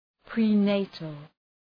{prı’neıtəl}
prenatal.mp3